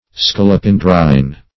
Search Result for " scolopendrine" : The Collaborative International Dictionary of English v.0.48: Scolopendrine \Scol`o*pen"drine\, a. (Zool.) Like or pertaining to the Scolopendra.